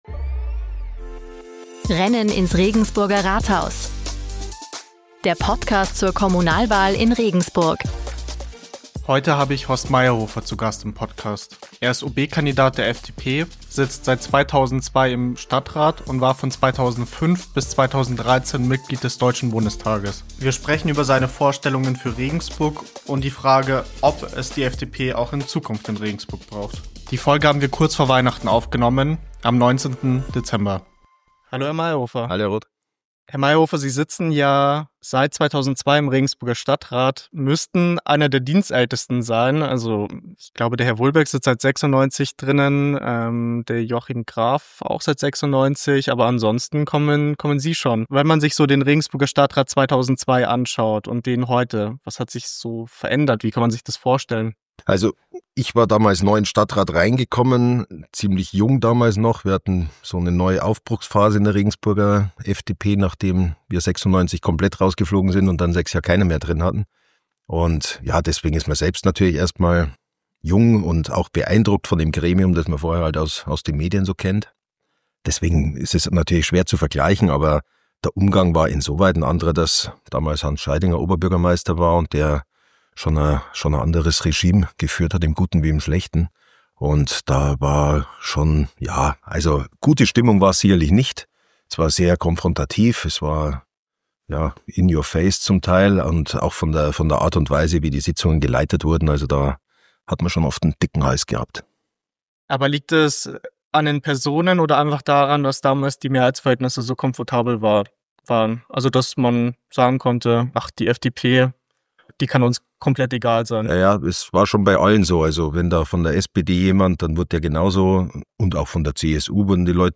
In dieser Folge ist Horst Meierhofer zu Gast, langjähriges Mitglied des Regensburger Stadtrats und ehemaliger Bundestagsabgeordneter der FDP.